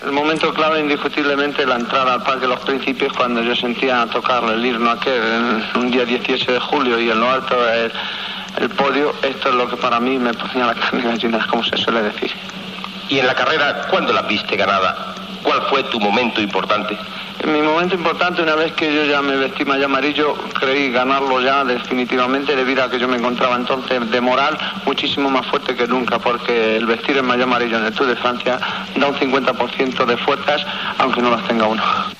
Entrevista al ciclista Federico Martín Bahamontes guanyador del Tour de França
Esportiu
Fragment extret del programa "La radio con botas", emès per Radio 5 l'any 1991